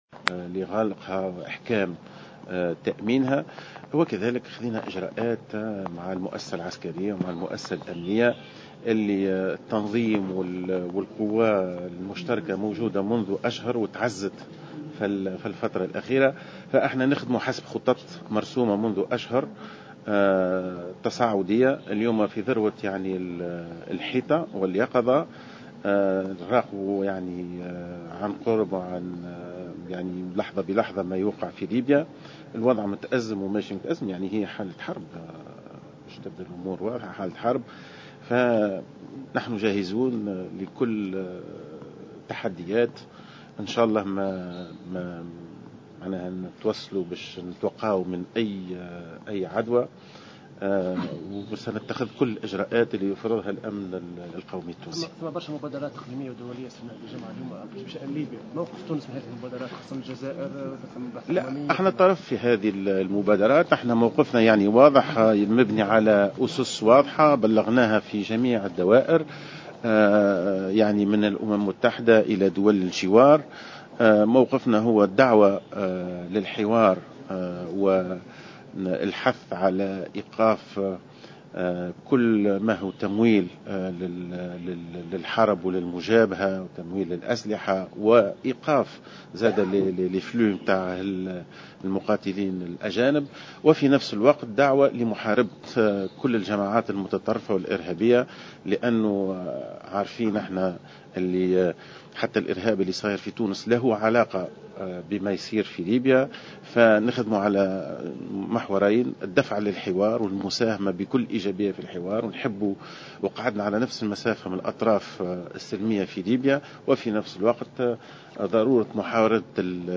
وصف رئيس الحكومة المؤقتة مهدي جمعة في تصريح إعلامي اليوم الاثنين ما يحصل في الأراضي الليبية بالحرب الحقيقية، مؤكدا أن الحكومة ستتخذ كل الإجراءات التي تقتضيها حماية الأمن القومي التونسي.